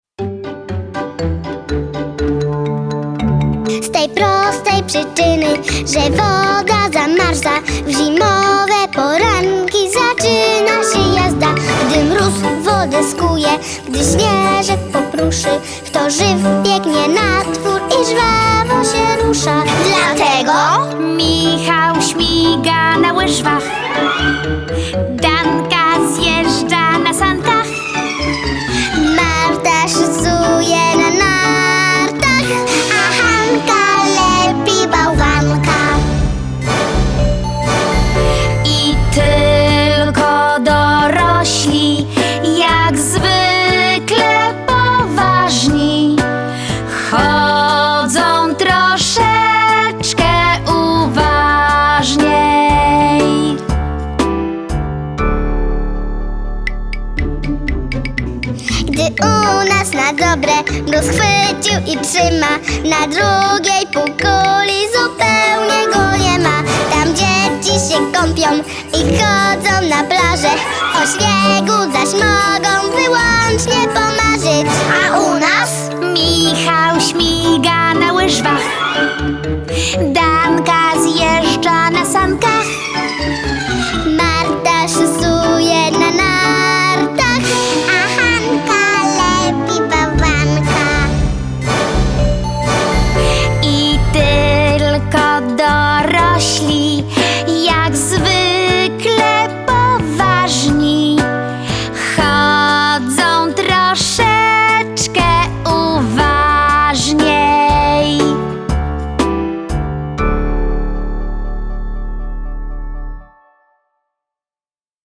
Zimowa jazda (wersja wokalno-instrumentalna)
Genre: Blues.